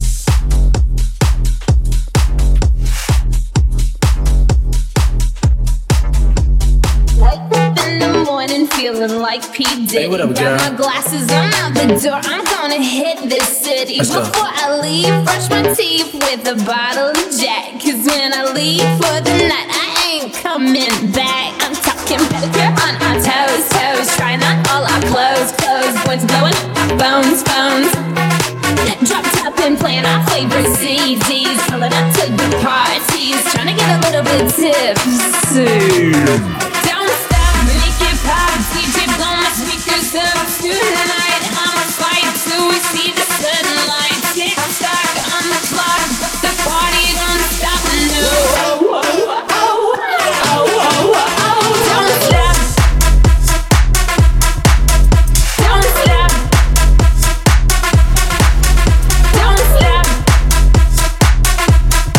Genre: MASHUPS Version: Clean BPM: 127 Time